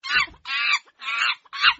Monyet_Suara.ogg